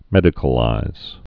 (mĕdĭ-kə-līz)